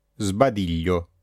S czytamy jak /z/:
2) przed spółgoskami dźwięcznymi: b, d, g, l, m, n, r, v: